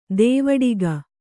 ♪ dēvaḍiga